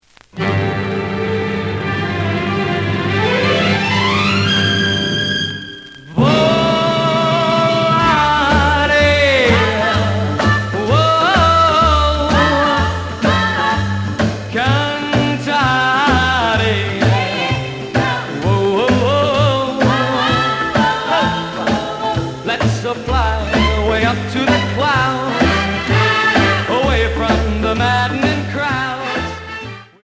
試聴は実際のレコードから録音しています。
●Genre: Rhythm And Blues / Rock 'n' Roll
●Record Grading: VG- (両面のラベルにダメージ。盤に若干の歪み。プレイOK。)